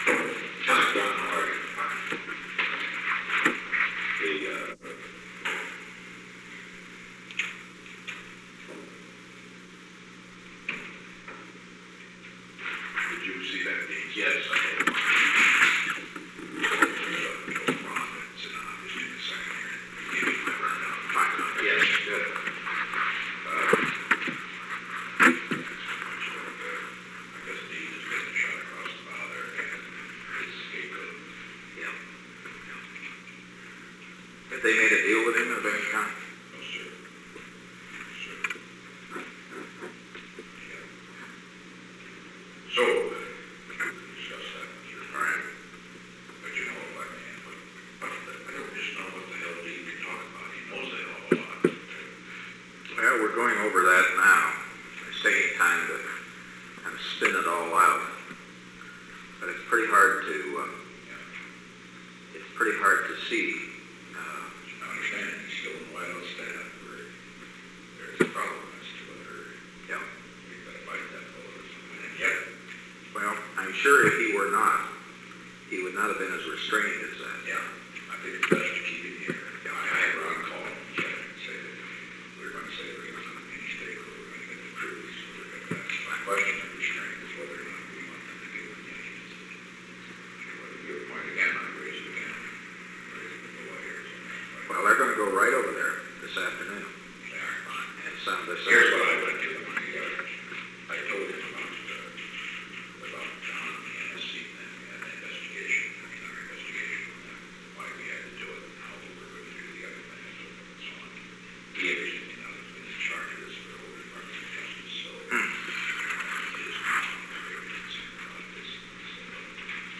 Conversation No. 902-9 Date: April 19, 1973 Time: 1:03 pm - 1:30 pm Location: Oval Office The President met with John D. Ehrlichman.
Secret White House Tapes